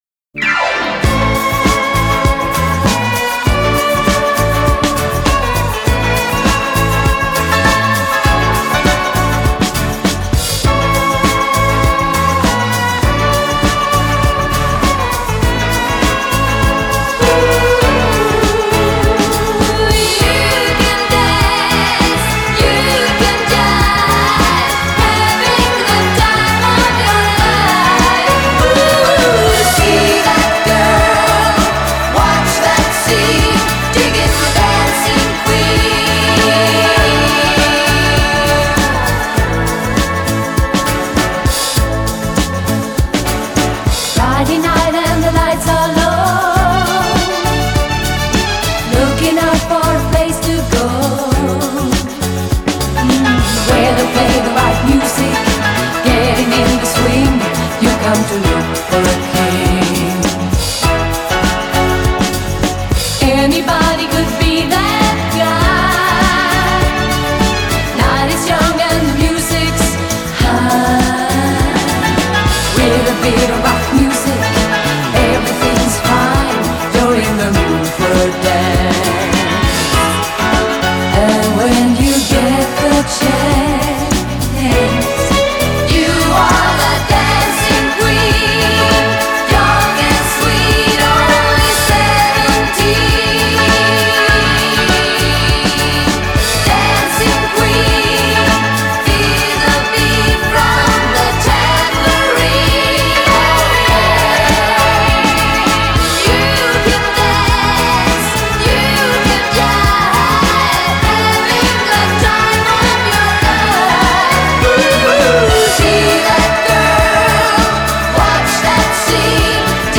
Жанр: Pop
веселая песня